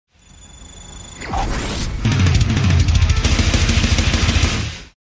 levelup.mp3